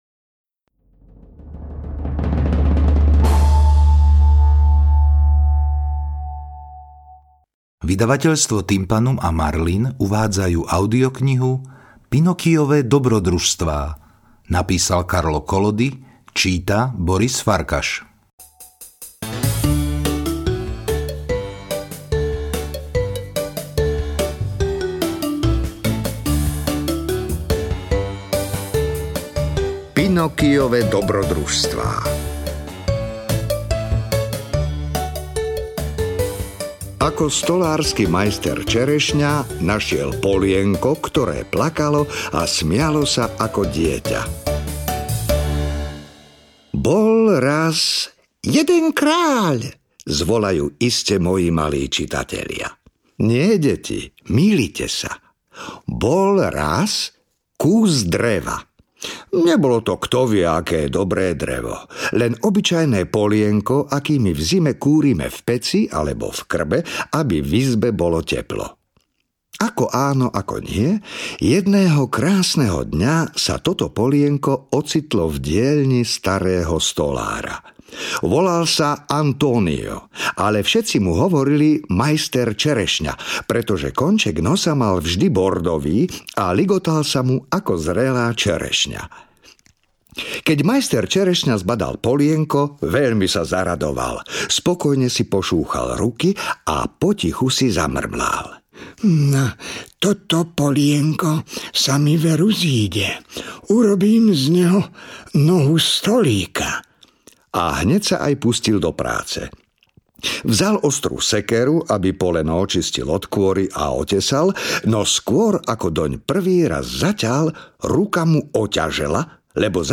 Audionahrávka ke knize Pinocchiove dobrodružstvá ve formátu MP3.